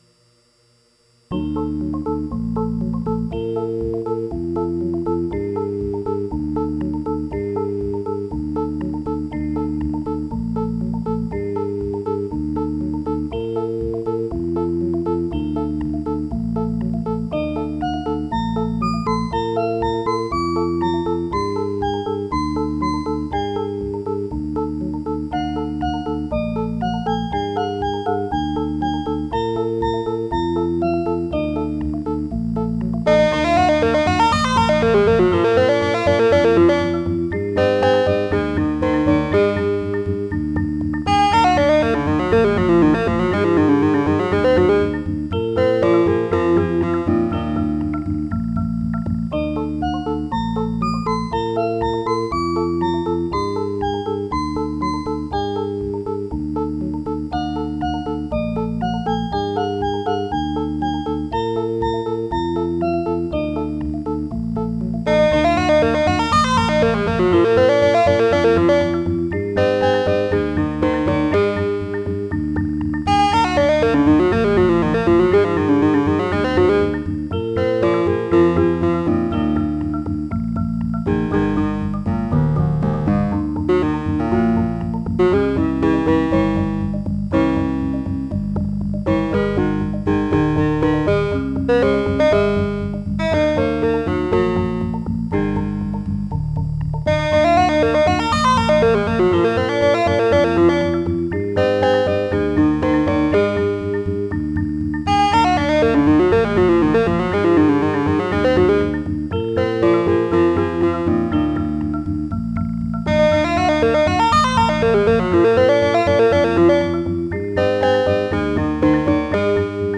Sound quality is excellent using a 12-bit DAC, 32kHz sample rate and 32-bit precision DSP computations.
Produced using a MIDI sequencer app driving six Sigma-6 modules, this clip demos a few of the many different instrument sounds (presets) available.